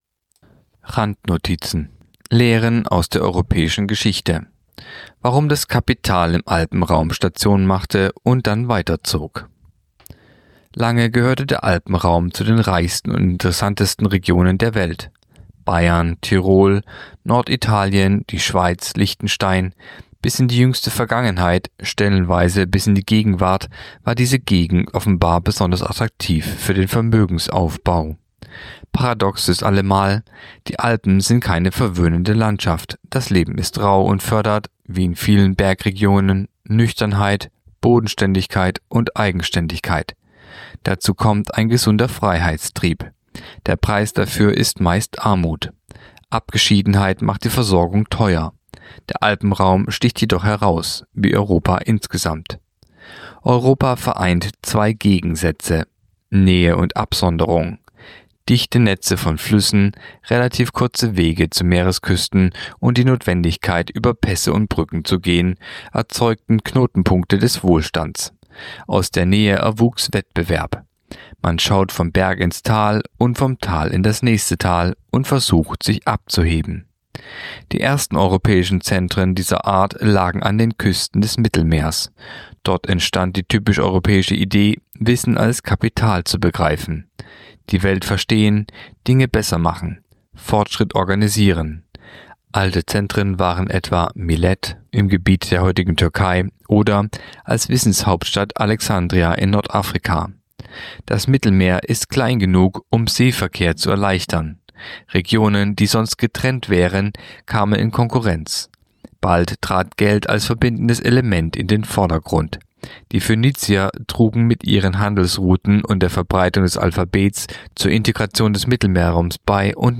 Artikel der Woche (Radio)Lehren aus der europäischen Geschichte